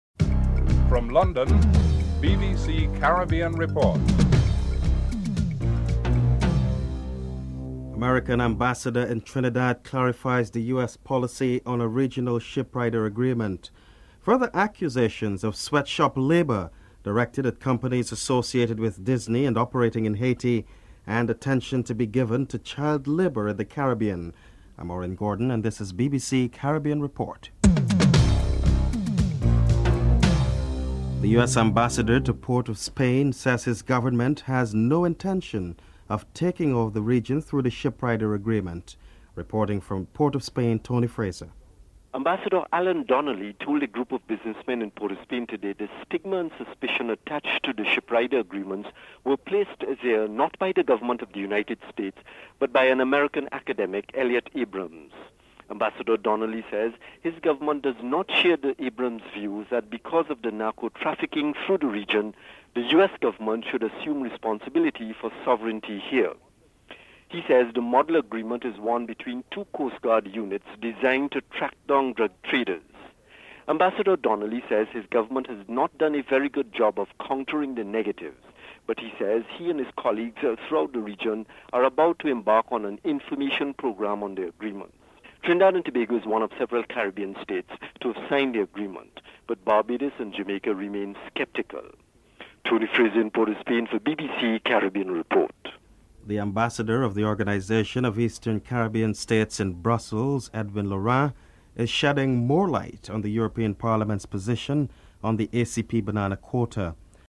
Ambassador of OECS in Brussels, Edwin Laurent is interviewed (01:36-05:04)